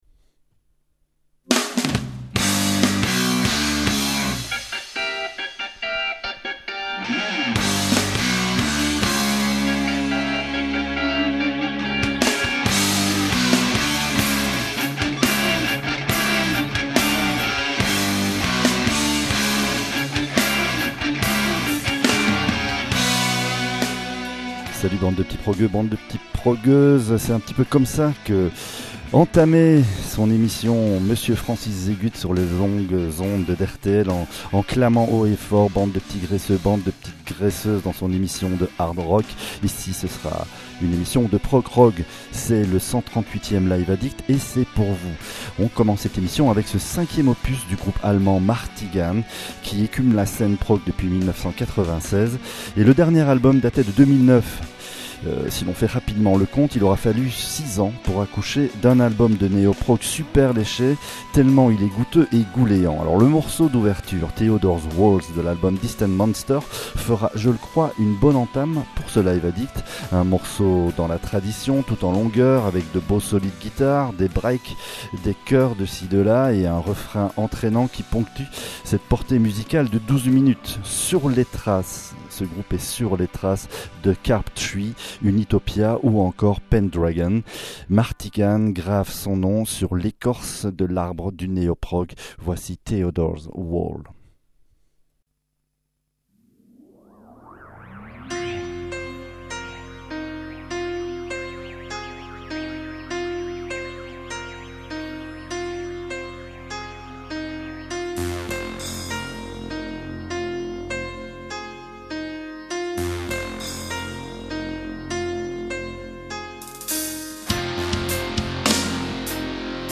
rock , rock progressif